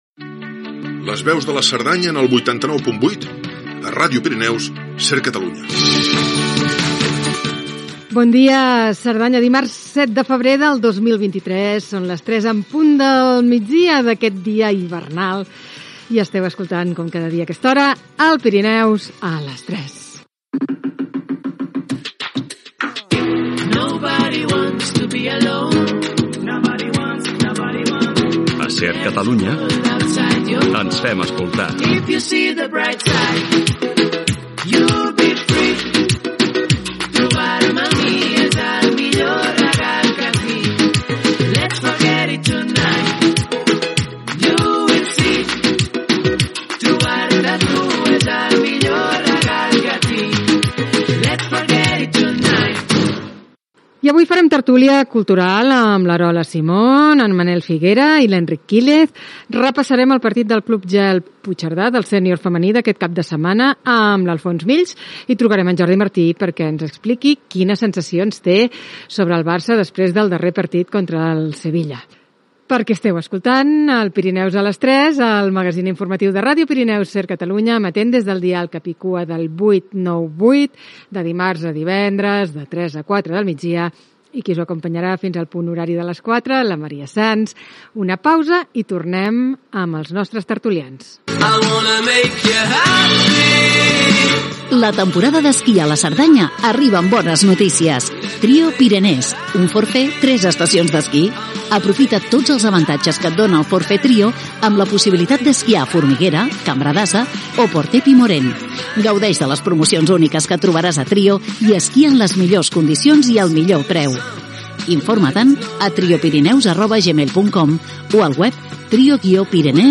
Indicatiu, presentació, sumari, publicitat, presentació dels integrants de la tertúlia de cultura
Info-entreteniment